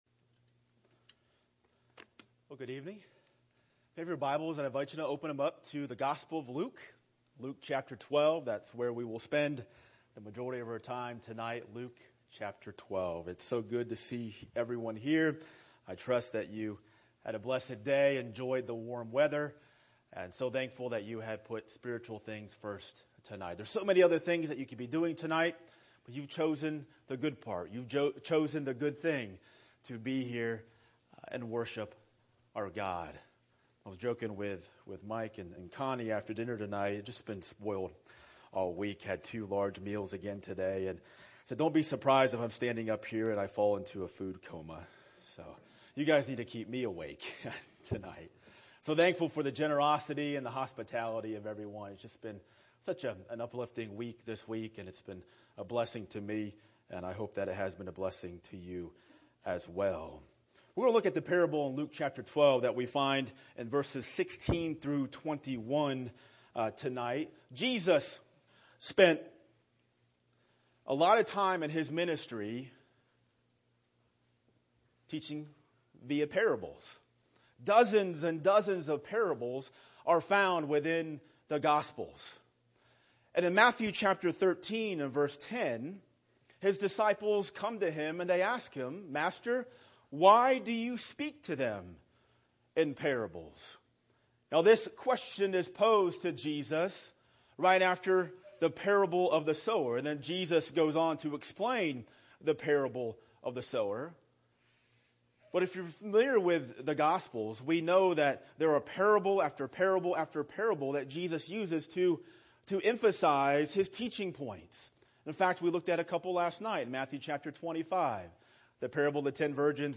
Weekly Sermons ← Newer Sermon Older Sermon →